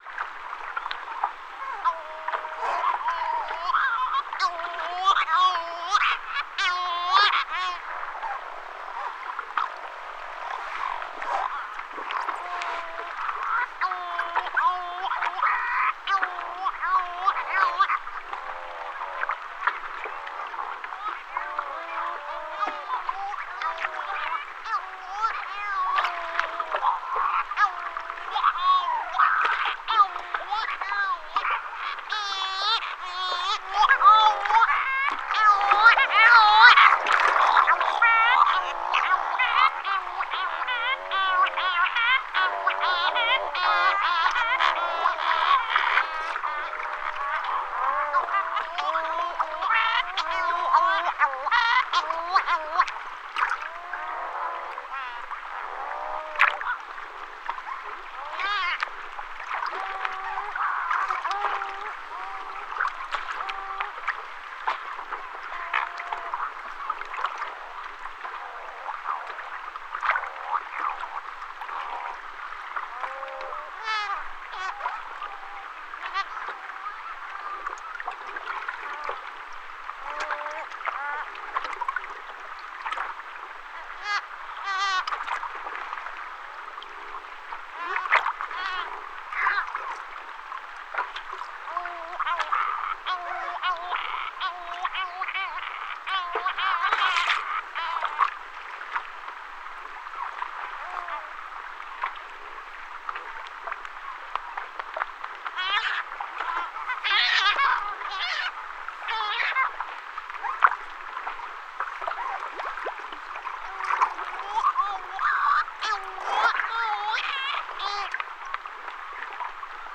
collectif field recording